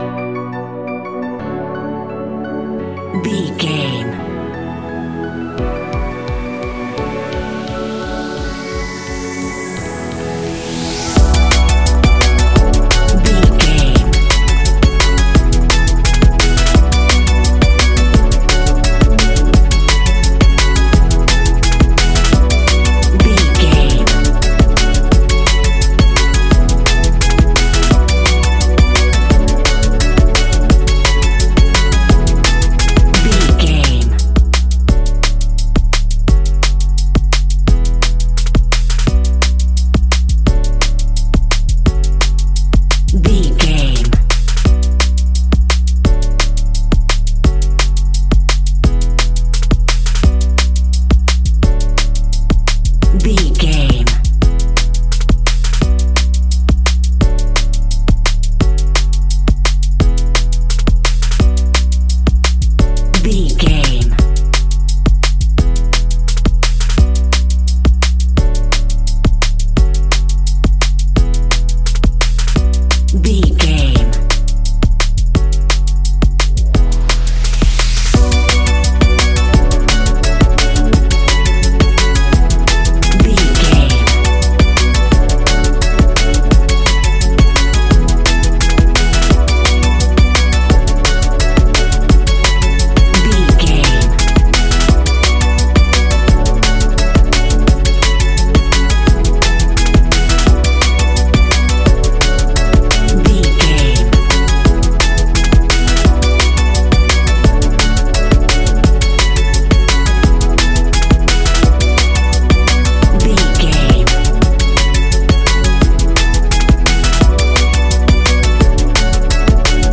Ionian/Major
techno
trance
synths
synthwave